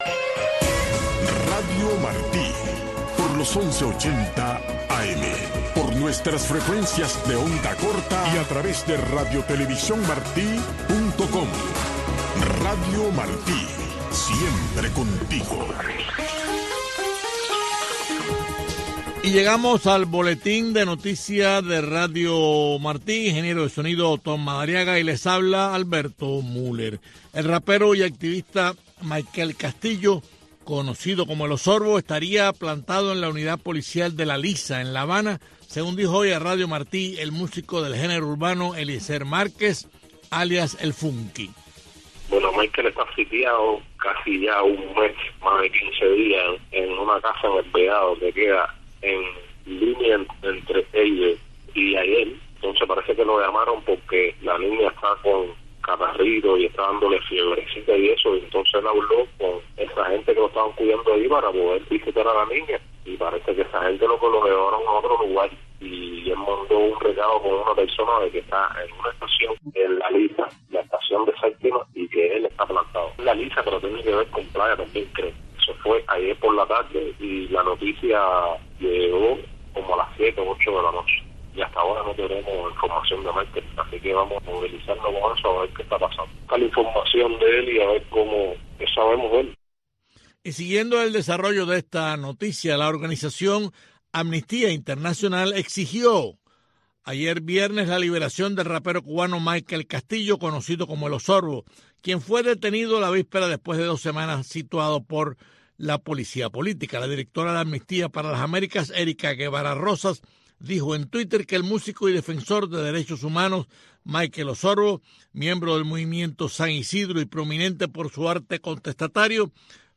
Un desfile de éxitos de la música pop internacional, un conteo regresivo con las diez canciones más importantes de la semana, un programa de una hora de duración, diseñado y producido a la medida de los jóvenes cubanos.